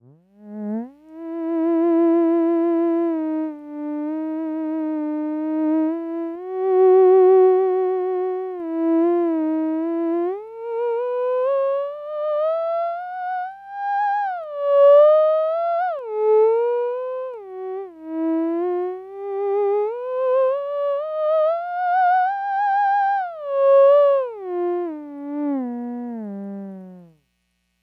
Authentic Theremin Voice .mp3 290k
This is Raw direct to sound card, no reverb or effects
A natural sound rich in even harmonics for a voice that is sculpted by the users own adjustments.
Have the voice of a young Tenor
phoenix_tenor2.mp3